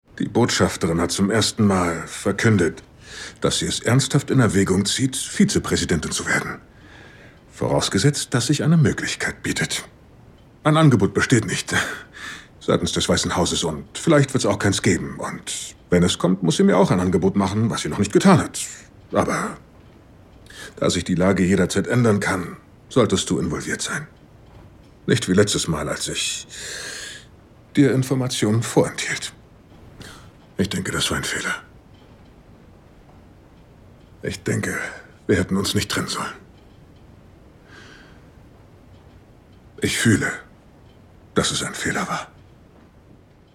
Werbung - Kaufland